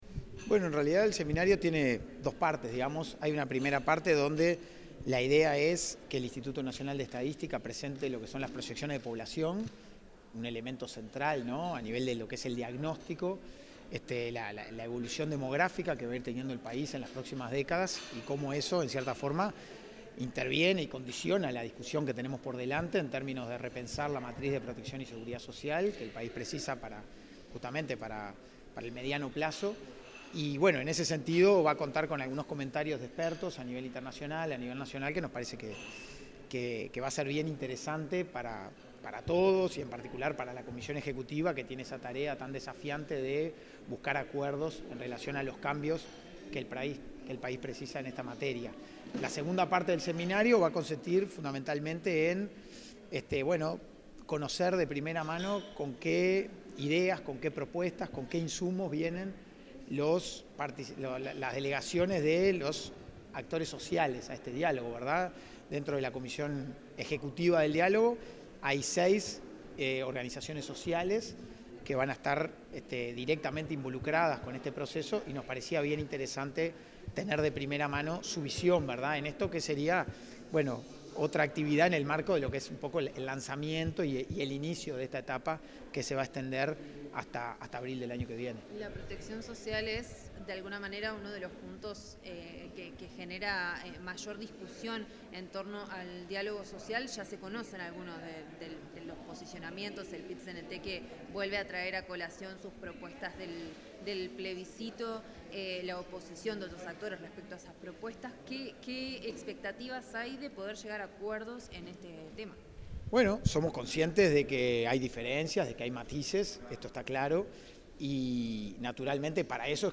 Declaraciones del coordinador de la Comisión Ejecutiva del Diálogo Social, Hugo Bai 31/07/2025 Compartir Facebook X Copiar enlace WhatsApp LinkedIn En el marco del primer seminario del Diálogo Social, el coordinador de la Comisión Ejecutiva de ese ámbito, Hugo Bai, efectuó declaraciones a la prensa.